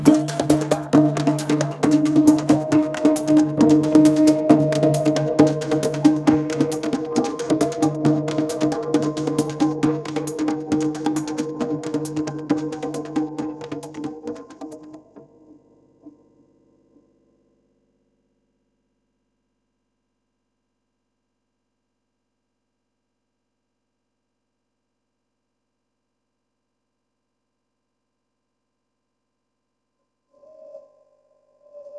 If someone wants to try generative AI music/looper maker I have a Colab that does that.